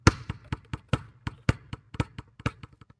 Basketball Dribble
A basketball being dribbled on a hardwood court with rhythmic bounces and hand slaps
basketball-dribble.mp3